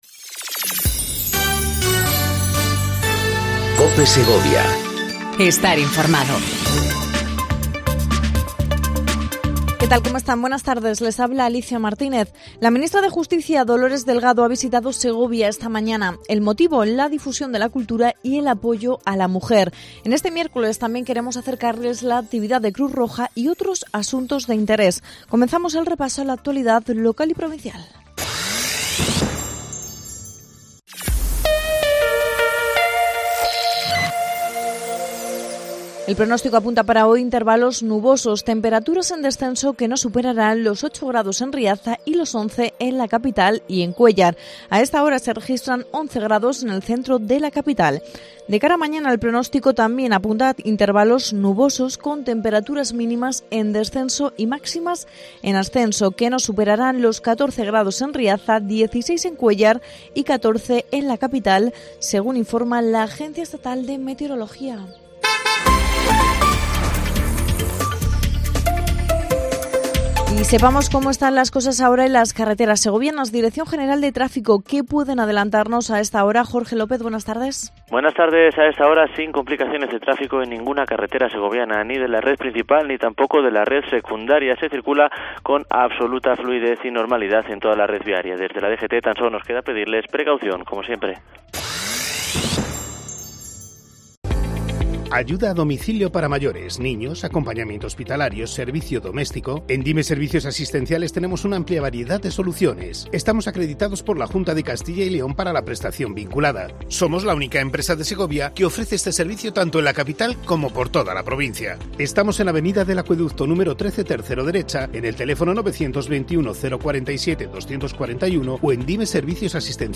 INFORMATIVO DEL MEDIODÍA EN COPE SEGOVIA 14:20 DEL 13/03/19